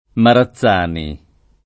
Marazzani [ mara ZZ# ni ] cogn.